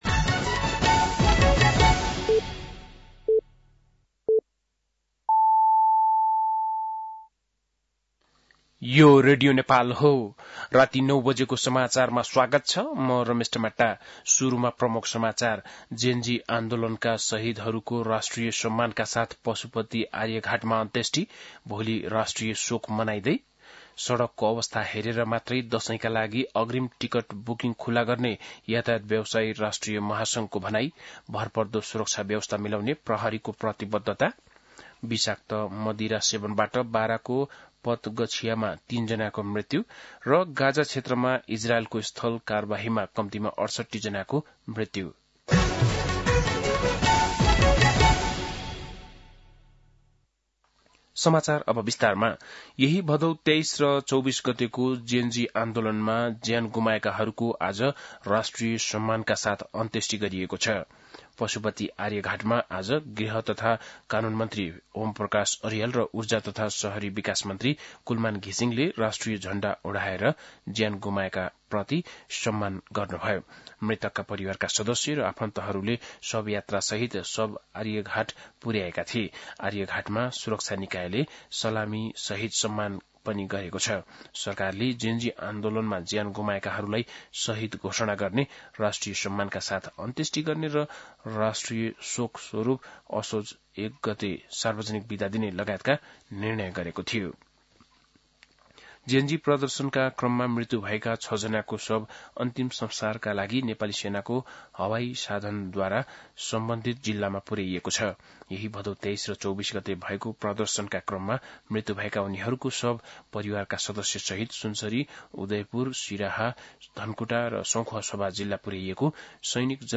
बेलुकी ९ बजेको नेपाली समाचार : ३१ भदौ , २०८२